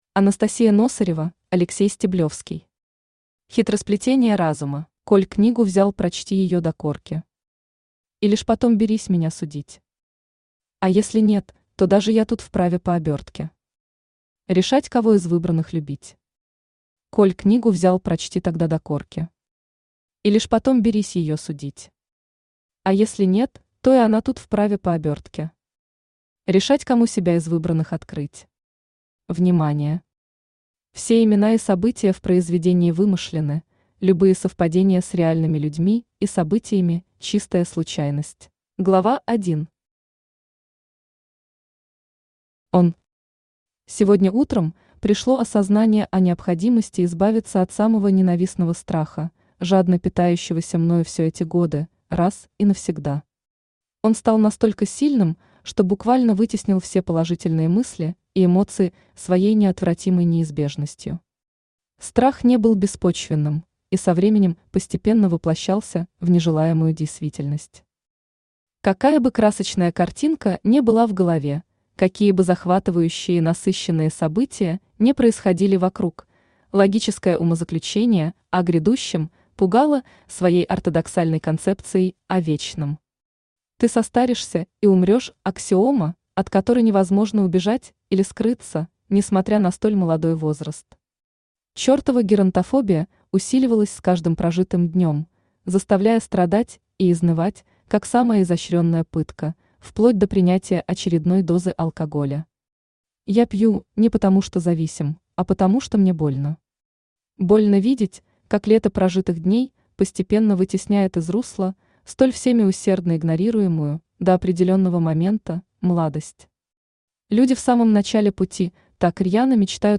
Аудиокнига Хитросплетения разума | Библиотека аудиокниг
Aудиокнига Хитросплетения разума Автор Анастасия Носырева Читает аудиокнигу Авточтец ЛитРес.